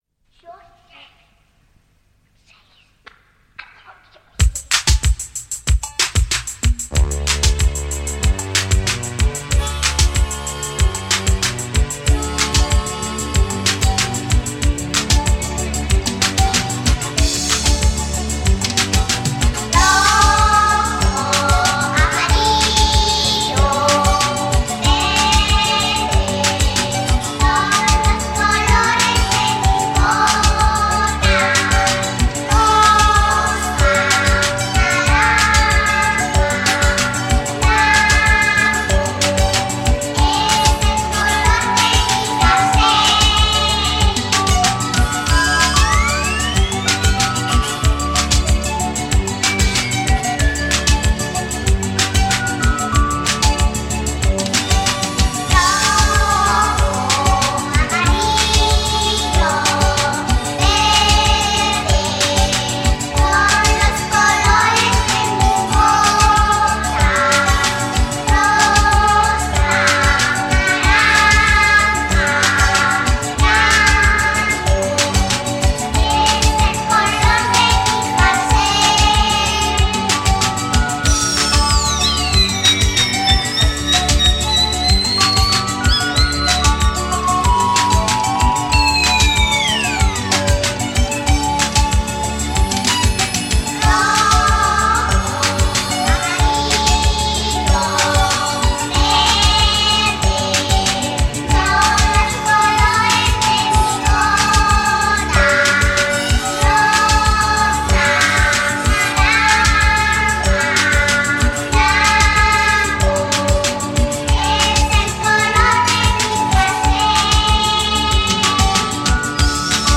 スペイン産NEW WAVE～アンビエント～プログレの奇跡